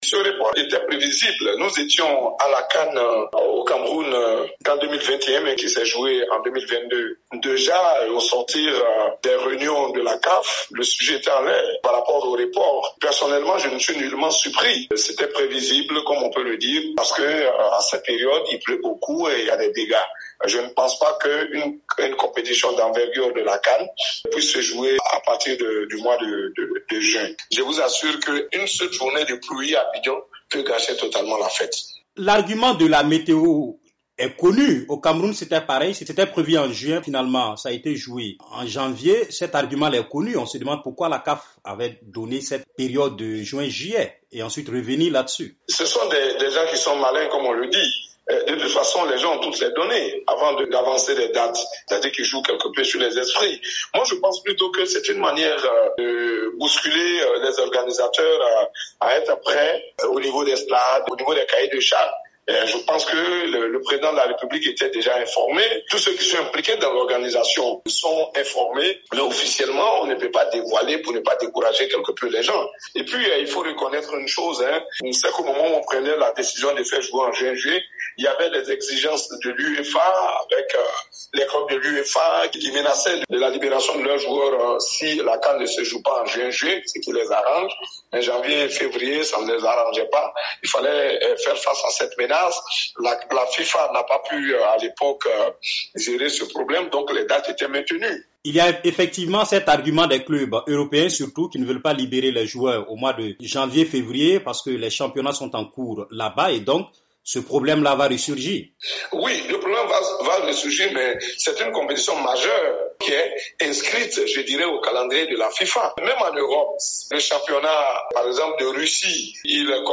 L'instance africaine du ballon rond évoque les fortes pluies à cette période en Côte d'Ivoire pour justifier ce report. Joint à Abidjan
le chroniqueur sportif